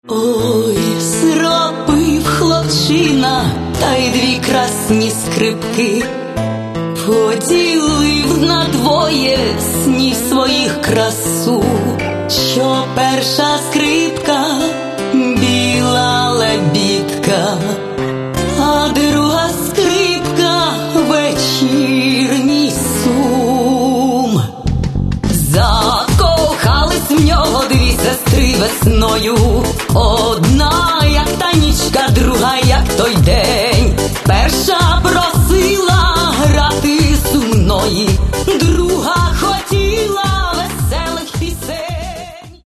в исполнении современных поп-артистов.